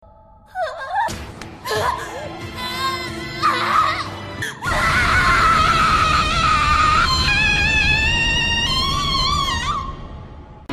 Play, download and share BRS Scream original sound button!!!!